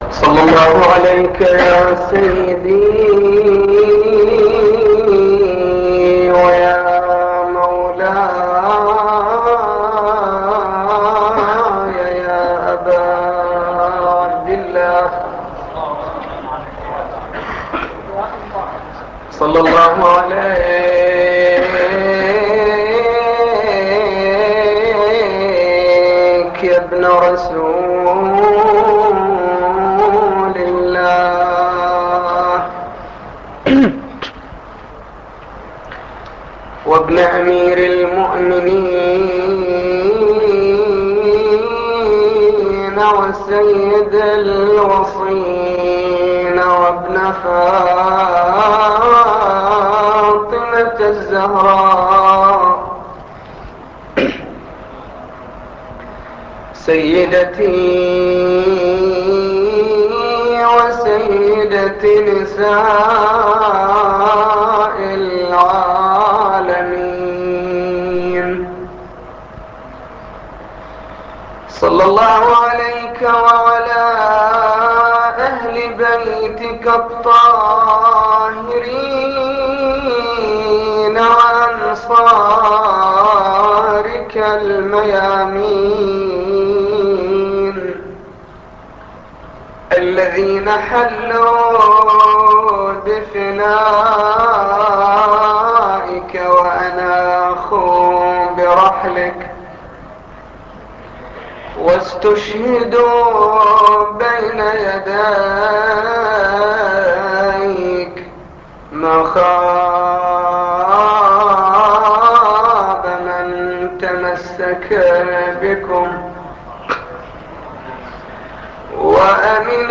مجلس حسيني16 شهر رمضان